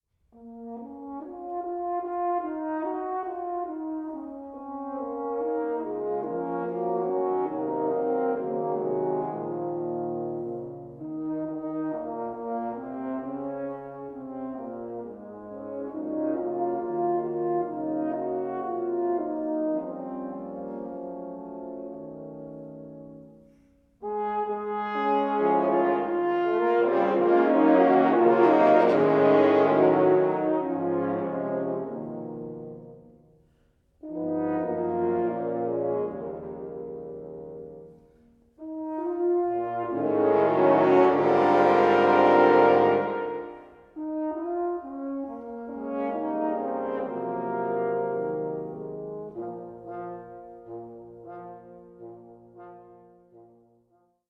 Stereo
percussion and drum kit